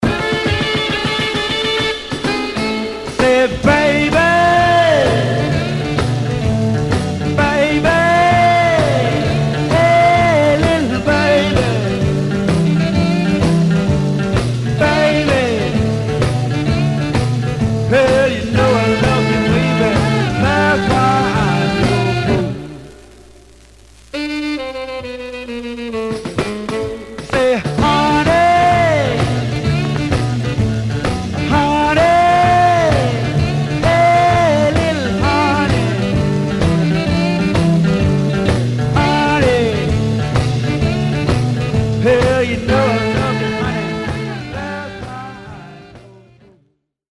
Stereo/mono Mono
Rythm and Blues Condition